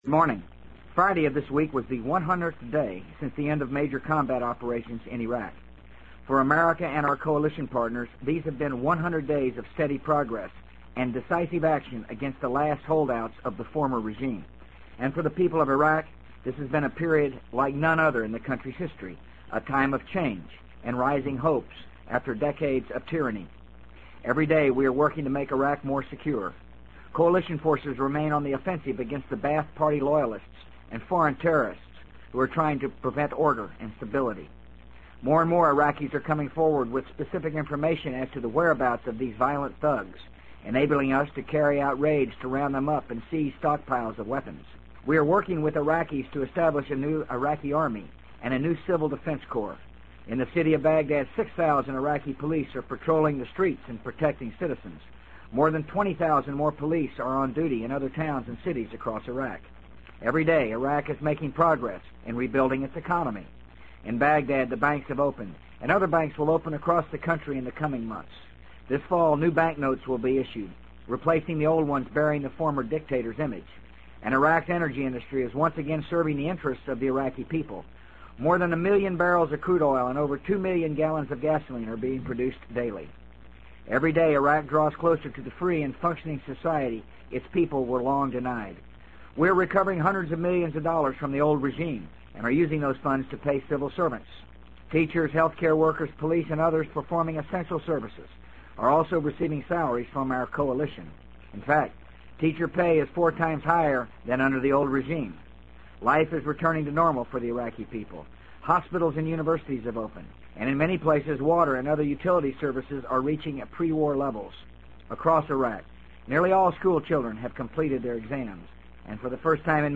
【美国总统George W. Bush电台演讲】2003-08-09 听力文件下载—在线英语听力室